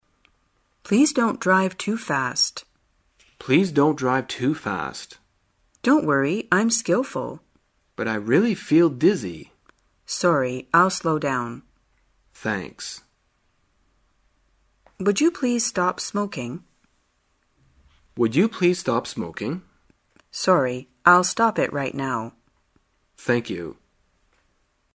在线英语听力室生活口语天天说 第90期:怎样提出要求的听力文件下载,《生活口语天天说》栏目将日常生活中最常用到的口语句型进行收集和重点讲解。真人发音配字幕帮助英语爱好者们练习听力并进行口语跟读。